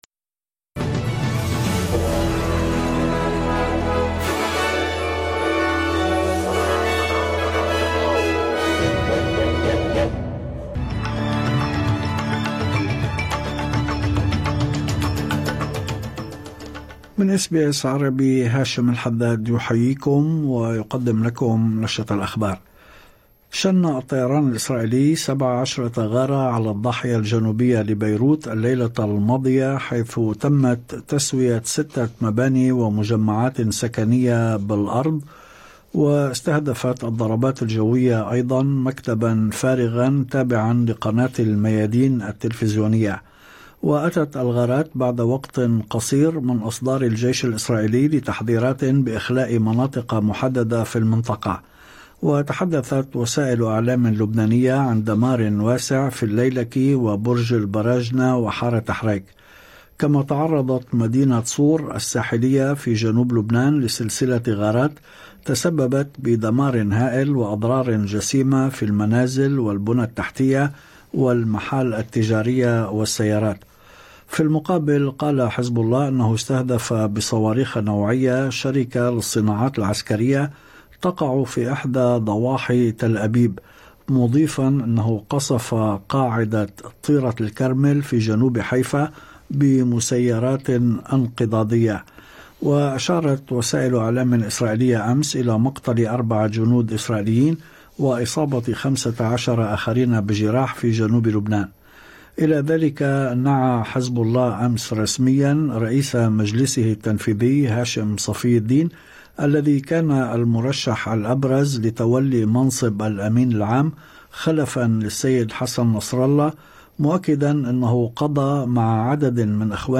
نشرة أخبار الظهيرة 24/10/2024